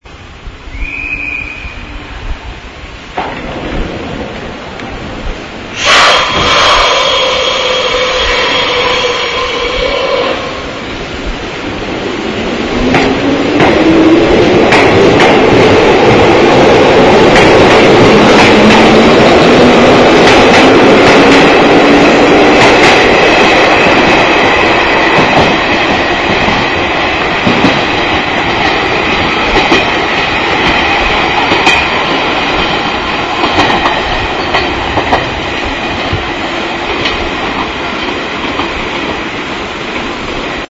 元テープは古く、また録音技術も悪いため、音質は悪いかも知れません。
F　８０９Ｆ　（２３８ＫＢ　４０秒）　８２年　東村山発西武園行き　発車音　冷改前の６７式独特のブレーキ緩解音入り